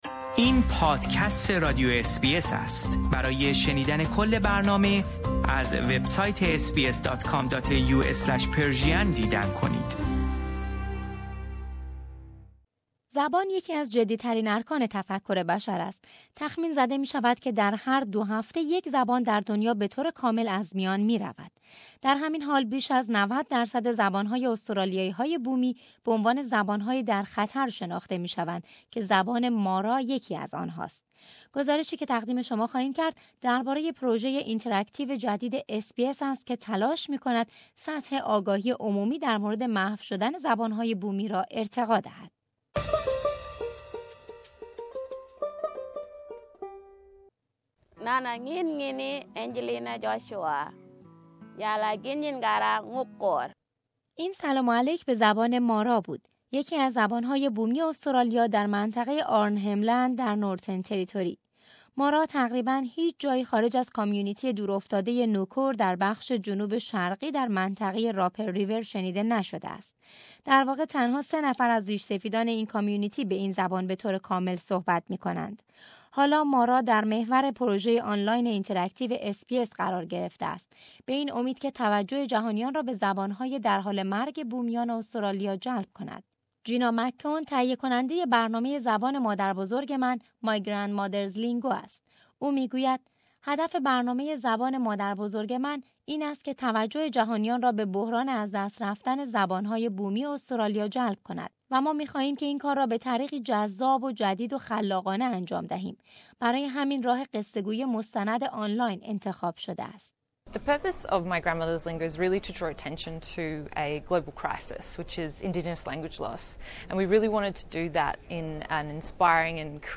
آنچه که در ابتدای گزارش می شنوید سلام و علیک به زبان مارا است، یکی از زبان‌های بومی استرالیا در منطقۀ آرنهم لند در نورترن تریتوری.